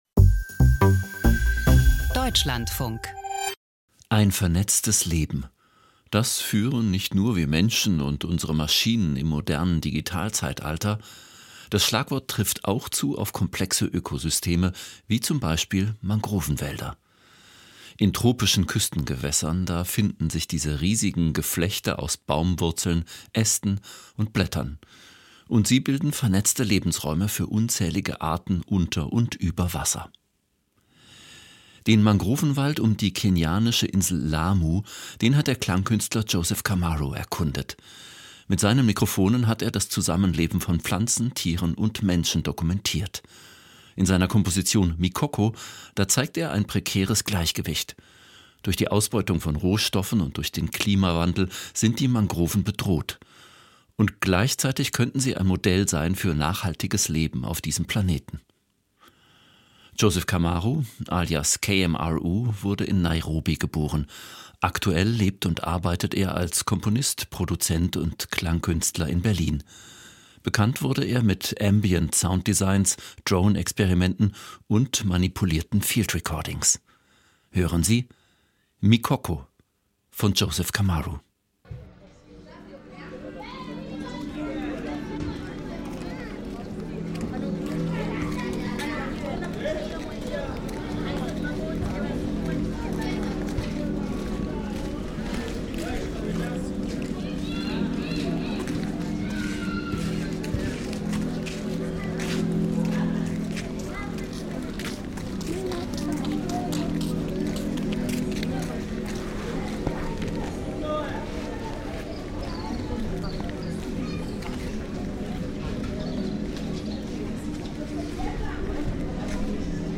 Klangkunst über Mangrovenwälder